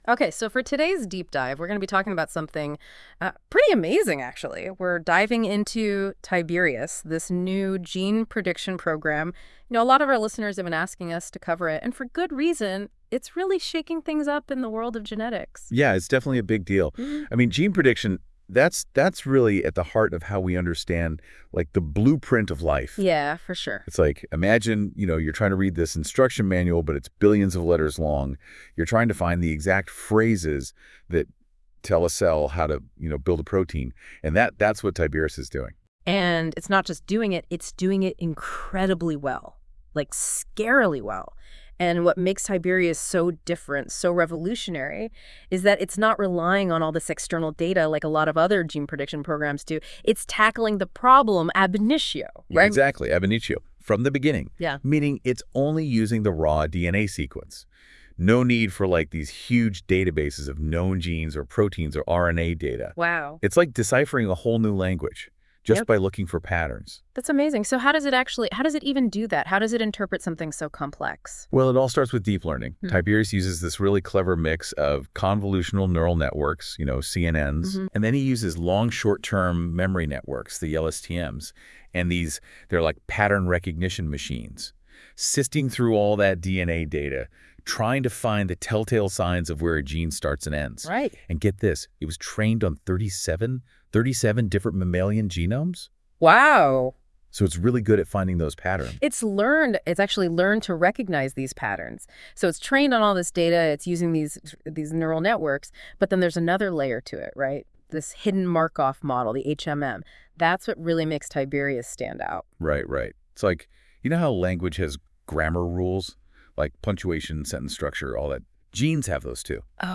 Popular science podcast on this paper generated with NotebookLM